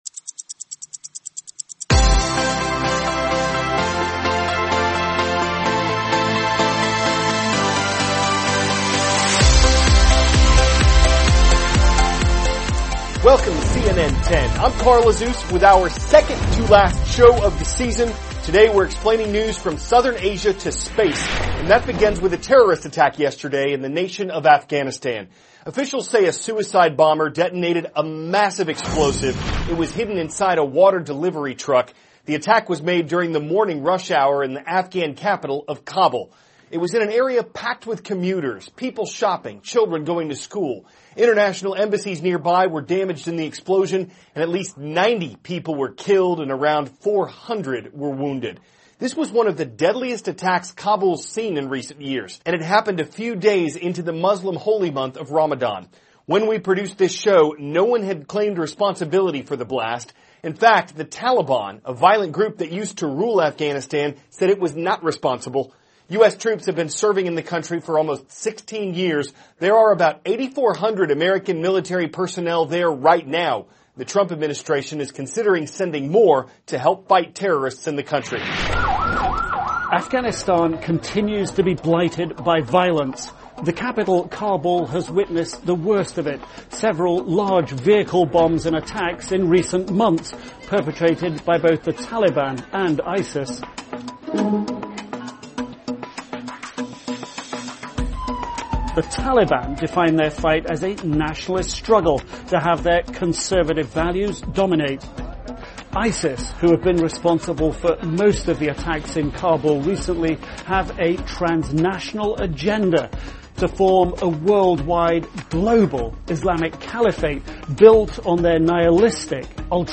(cnn Student News) -- June 1, 2017 Terrorists Target the Capital of Afghanistan; U.S. Tests Out a Missile Defense System; NASA Plans a Mission to Sun THIS IS A RUSH TRANSCRIPT.